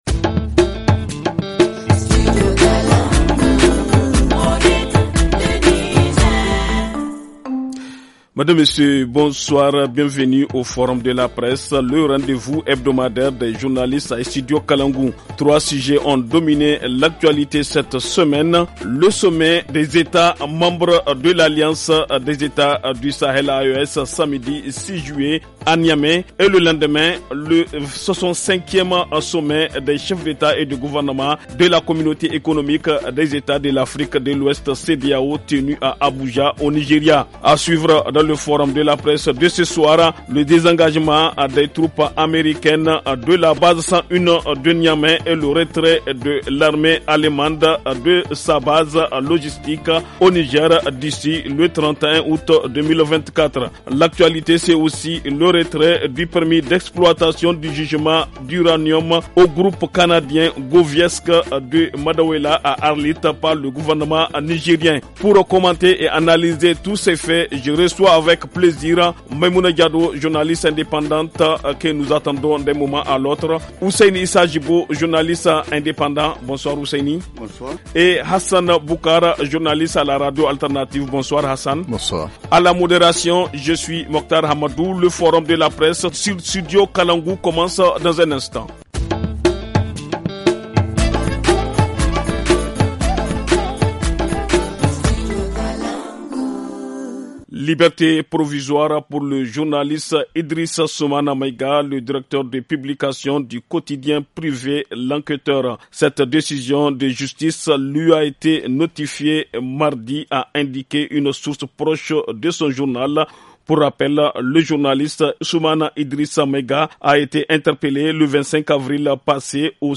Dans le forum de la presse de cette semaine : Le premier sommet à Niamey, des pays membres de l’AES, alliance des États du Sahel Le désengagement des troupes Américaines et le retrait de l’armée Allemande au Niger. Retrait du permis d’exploitation du gisement d’uranium d’Arlit, au groupe canadien GoviEx.